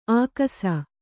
♪ ākasa